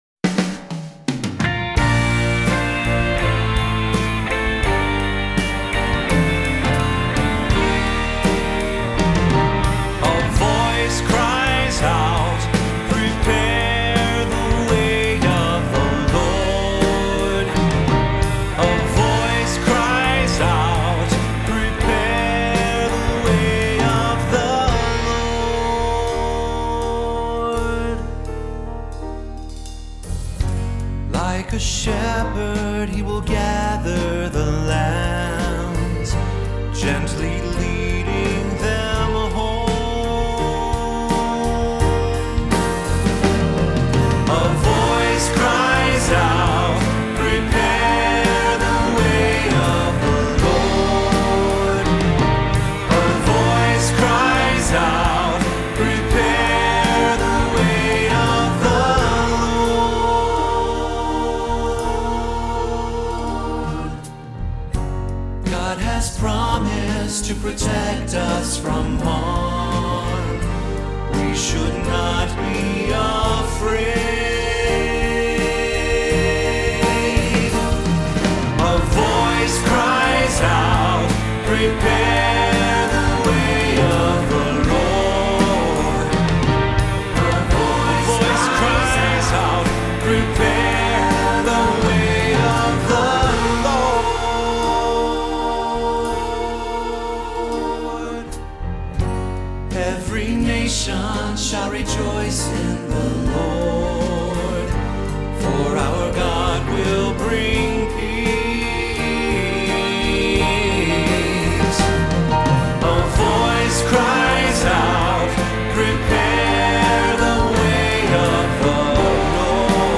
Voicing: Cantor, assembly,3-part Choir